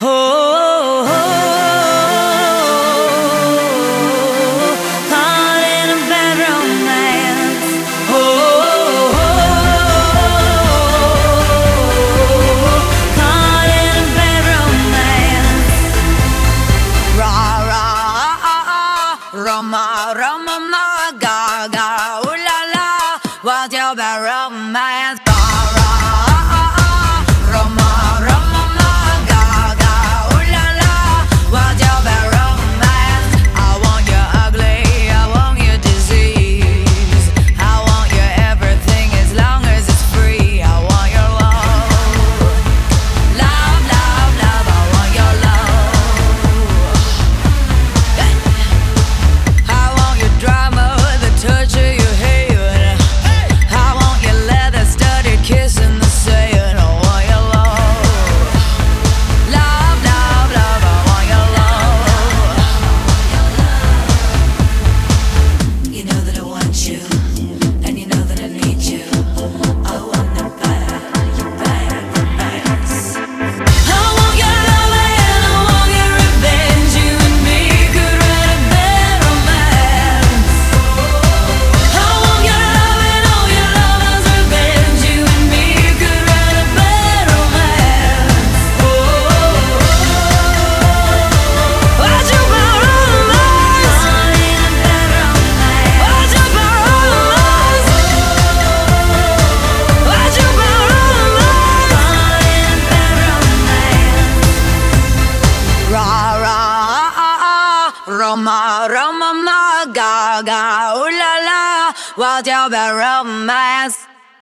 BPM119
Audio QualityMusic Cut